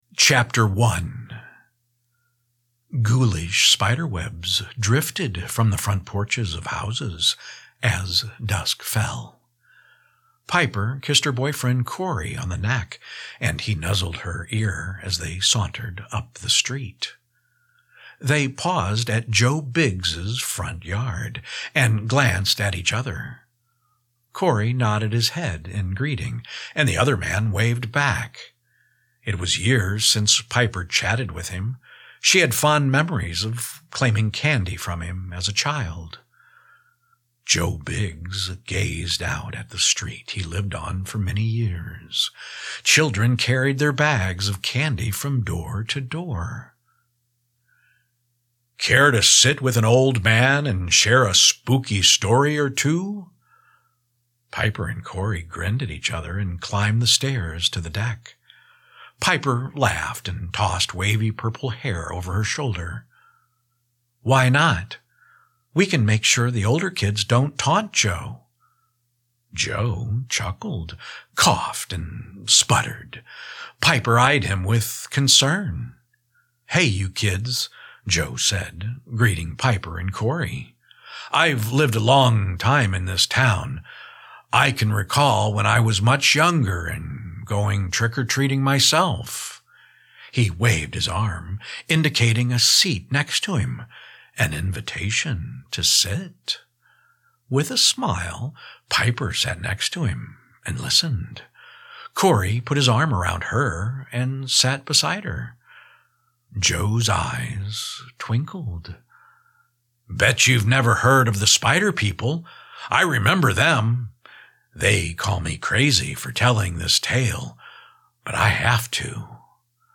Audiobook - Glenn Alan Productions